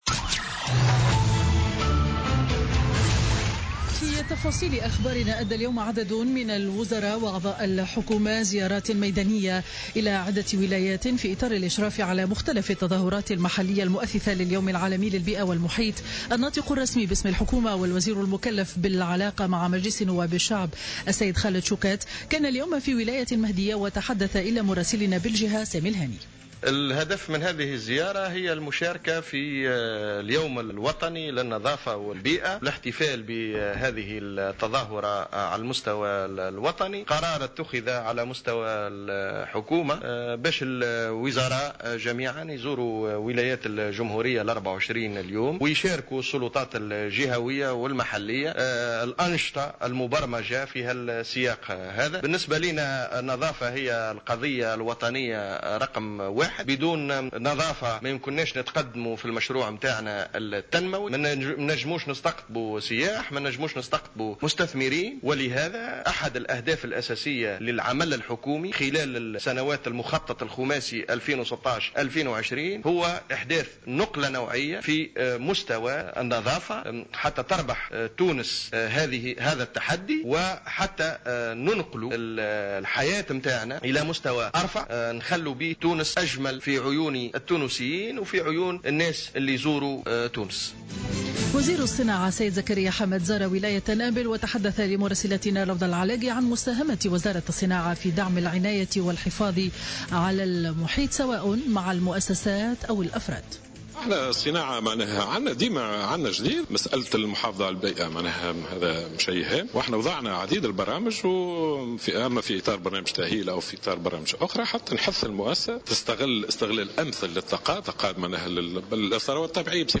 نشرة أخبار منتصف النهار ليوم الأحد 05 جوان 2016